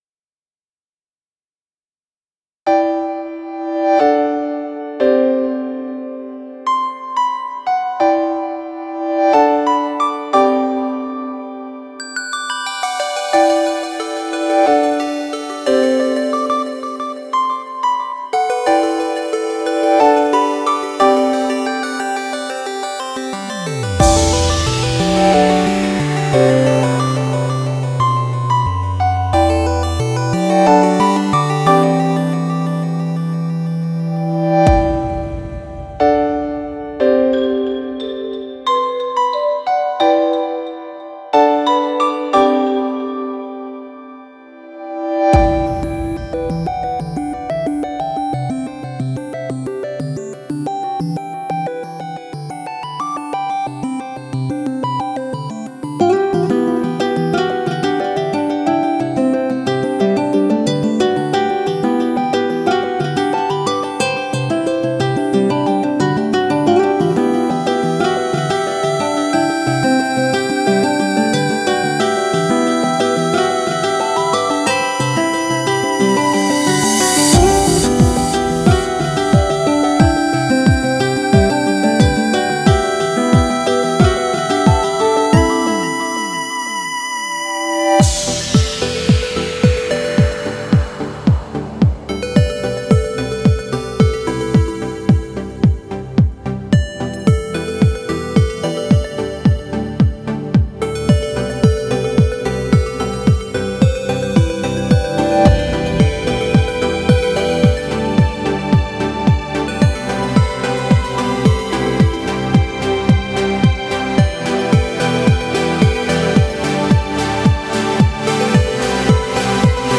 the guitar/chip/trance combo is just crazy
super peaceful, supporting the comfort.